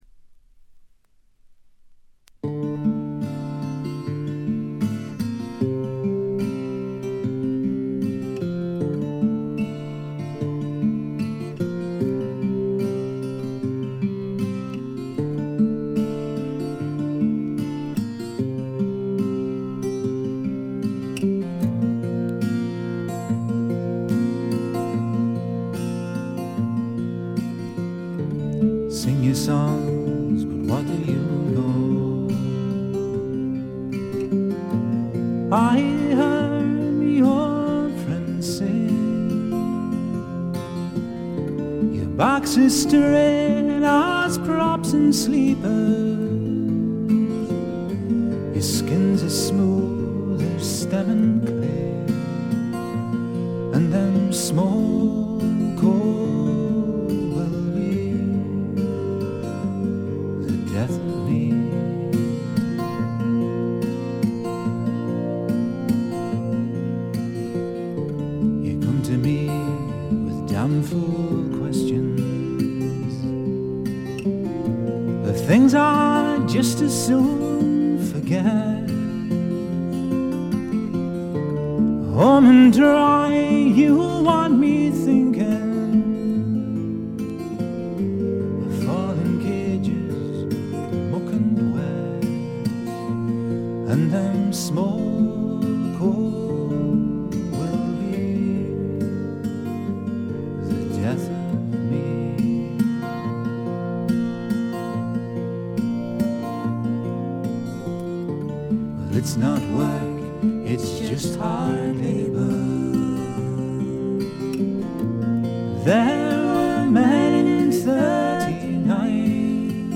ホーム > レコード：英国 フォーク / トラッド
部分試聴ですがチリプチ少々、散発的なプツ音少々。
試聴曲は現品からの取り込み音源です。